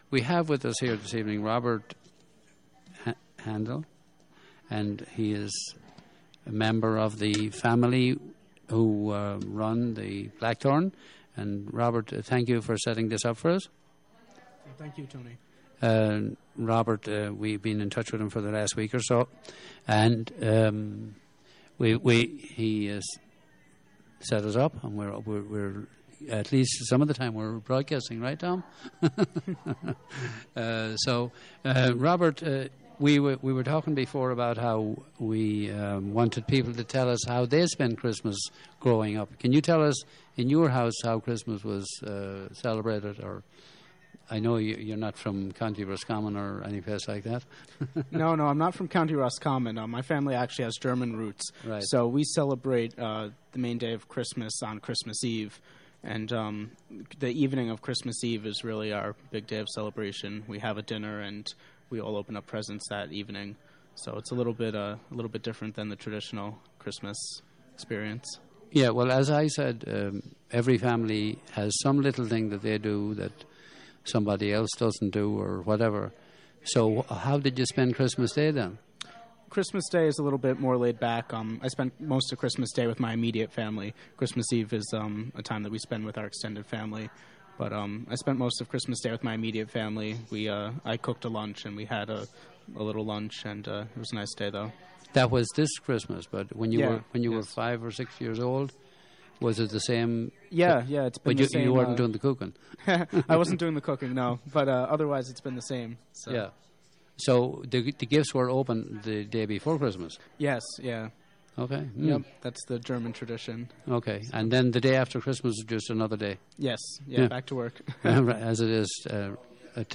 Interview
from live WGXC broadcast